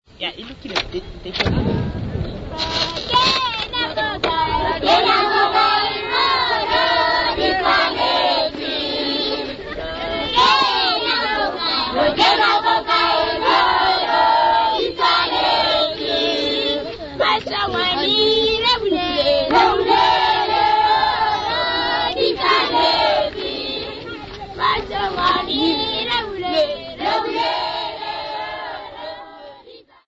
Sesotho boys and girls (Performer)
Shongoane village
Indigenous music
Wedding song
Unaccompanied Sesotho wedding song
Cassette tape